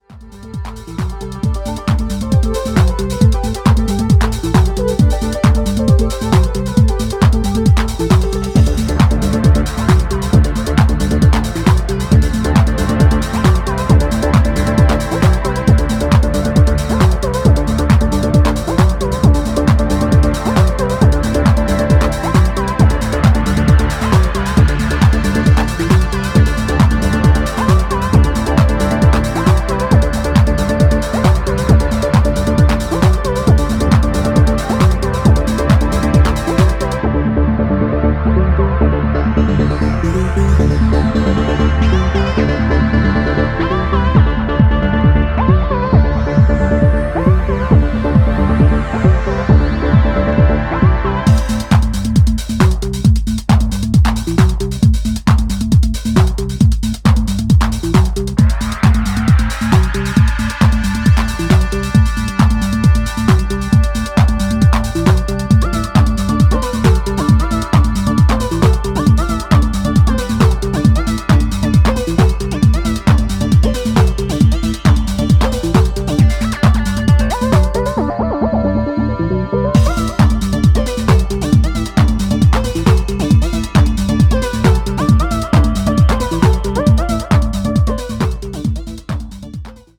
揺らめくシンセリフに視覚作用すら感じるユーフォリックなプロト・トランス